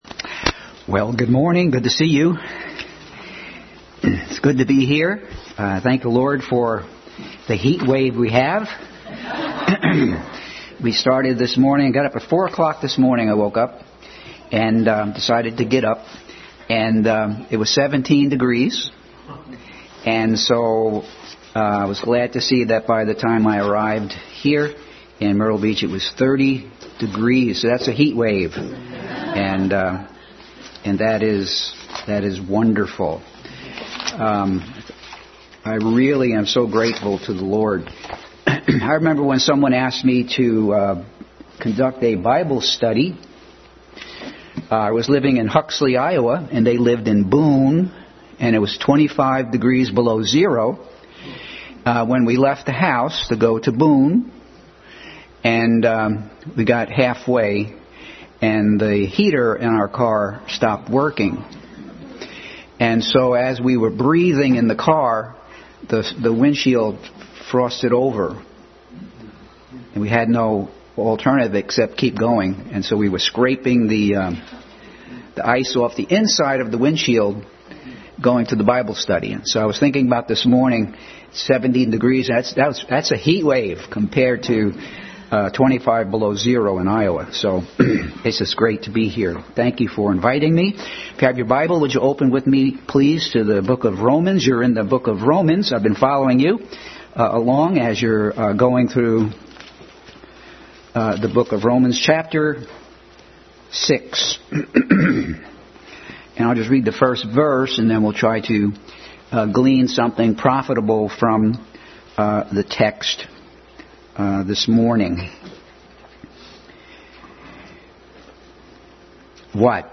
Adult Sunday School continued study in Romans.
Romans 6:21 Service Type: Sunday School Adult Sunday School continued study in Romans.